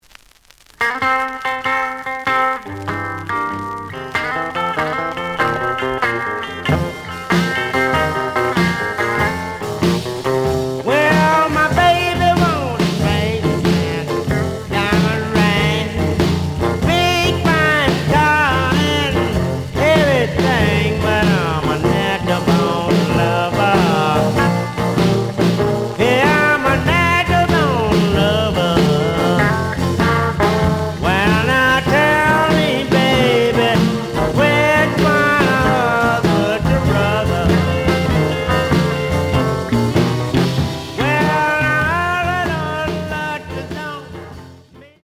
試聴は実際のレコードから録音しています。
The audio sample is recorded from the actual item.
●Genre: Blues